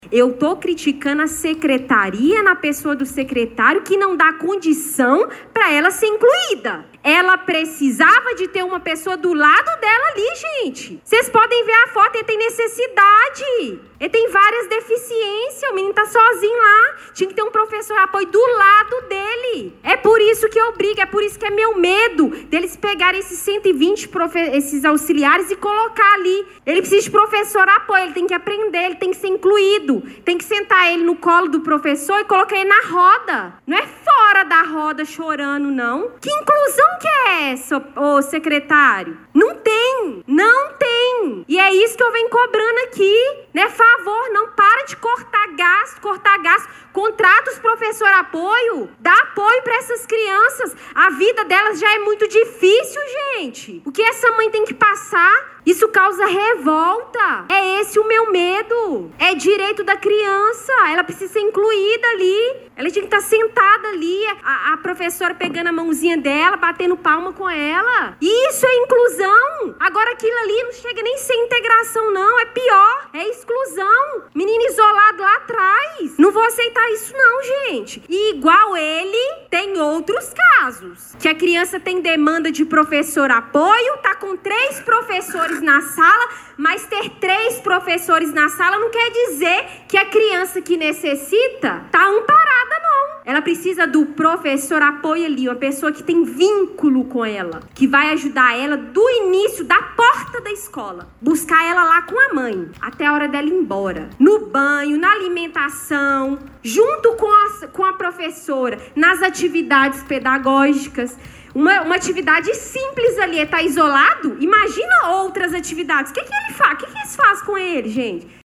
A reunião ordinária da Câmara Municipal de Pará de Minas, realizada ontem, 24 de março, foi marcada por debates e um forte desabafo com denúncias graves sobre a situação da educação inclusiva na cidade.